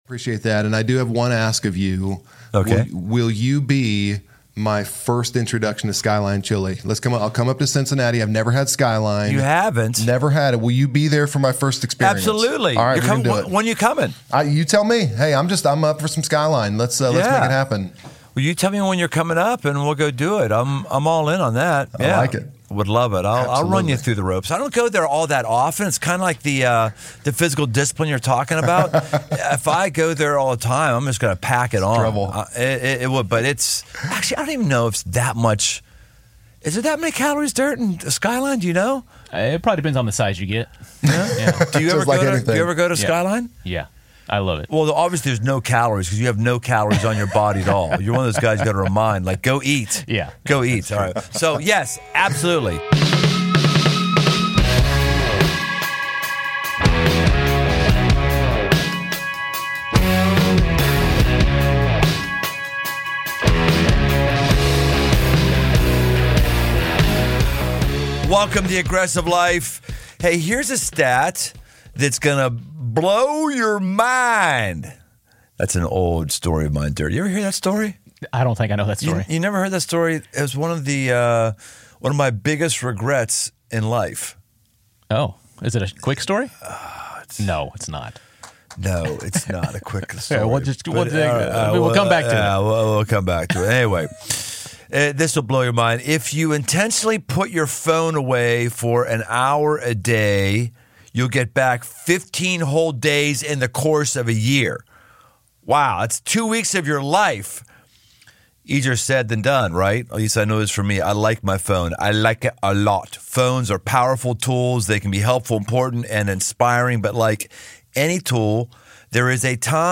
This aggressive conversation is full of practical wisdom to help you take steps toward improving your digital wellness.